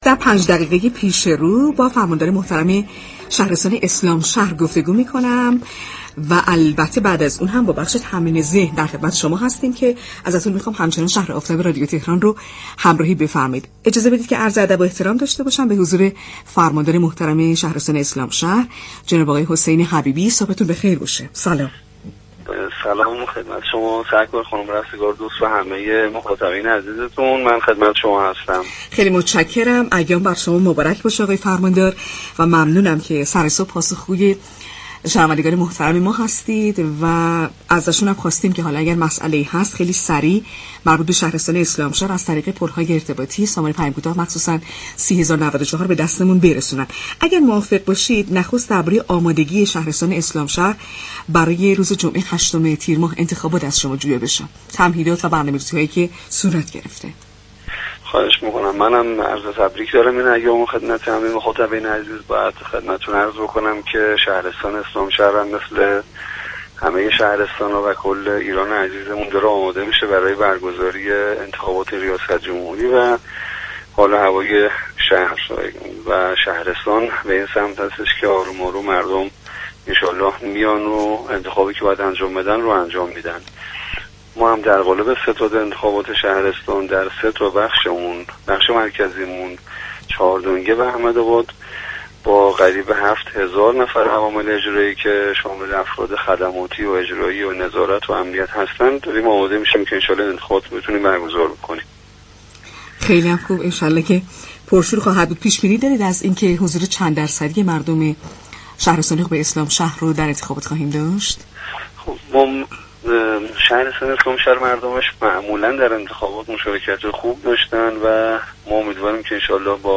به گزارش پایگاه اطلاع رسانی رادیو تهران، حسین حبیبی فرماندار شهرستان اسلامشهر در گفت و گو با «شهر آفتاب» اظهار داشت: مردم شهرستان اسلامشهر معمولا در انتخابات مشاركت خوبی داشتند؛ امیدواریم در این دوره از انتخابات هم مشاركت خوبی داشته باشم.